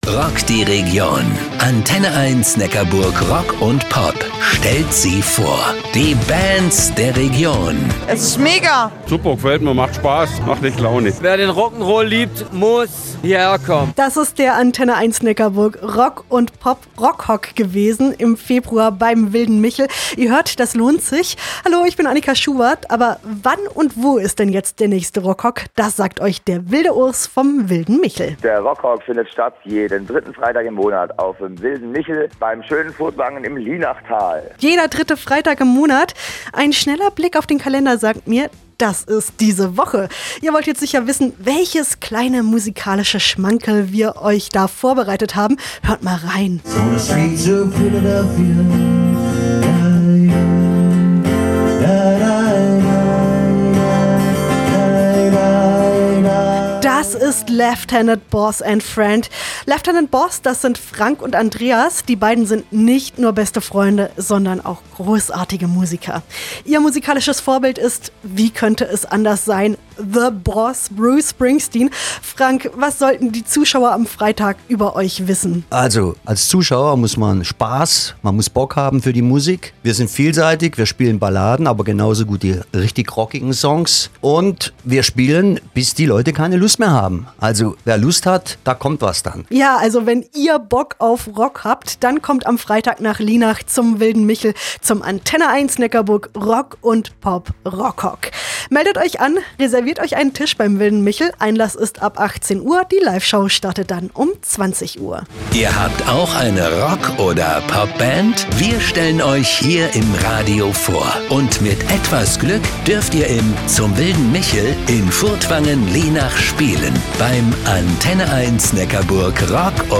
Interview Radio Antenne 1 Neckarburg Rock & Pop – Studio Rottweil 27.02.2025